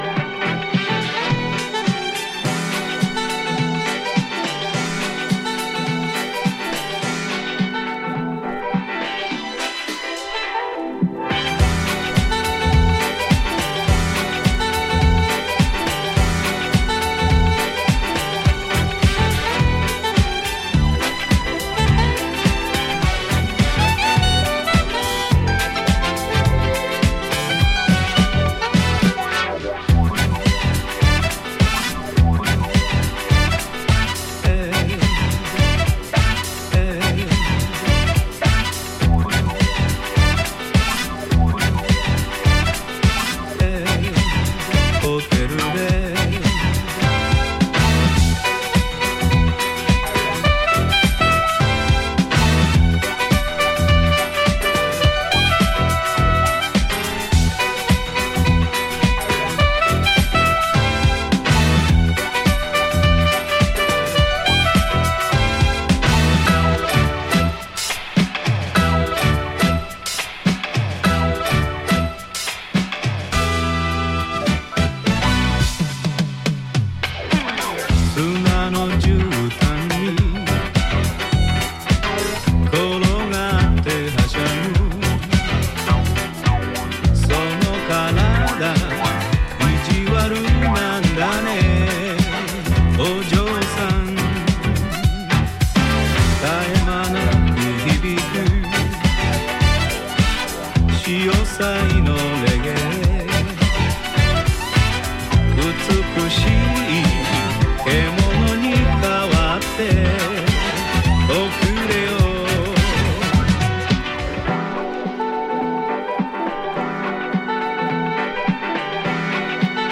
ジャンル(スタイル) DISCO / EDITS